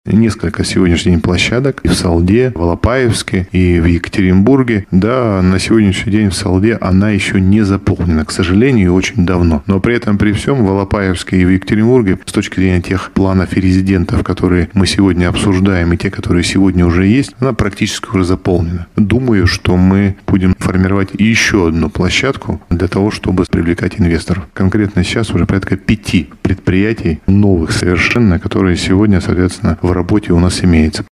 О развитии особой экономической зоны рассказал глава Свердловской области Денис Паслер на пресс-конференции в рамках Уральского медиафорума.